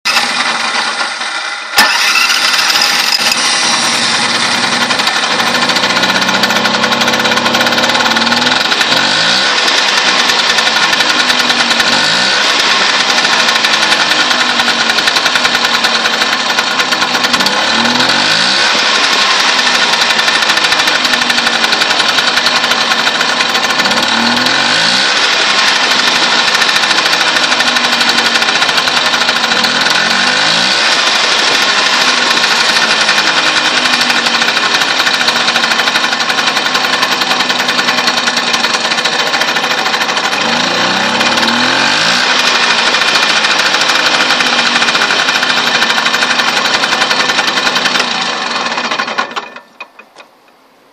Ich habe mal aufgenommen wie die aufs gas geht!!! Es ist aber schlecht da ich das aufnahmegerät nahe am Auspuff hatte.